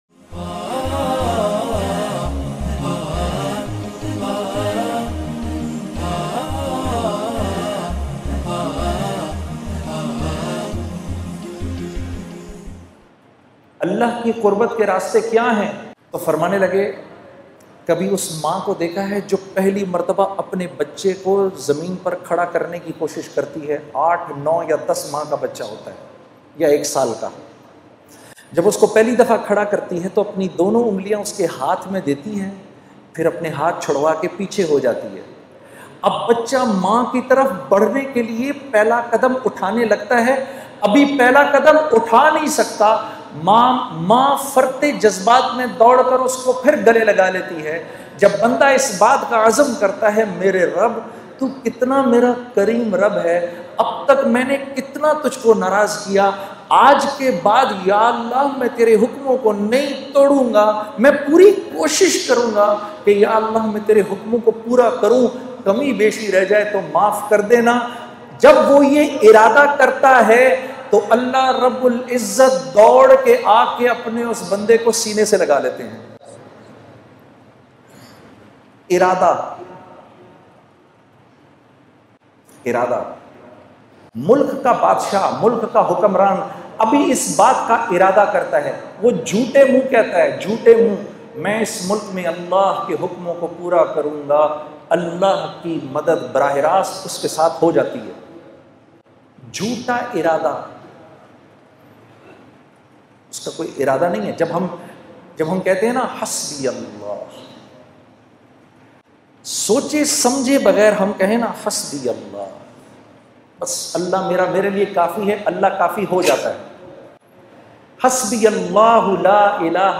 last-bayan-before-shahadat.mp3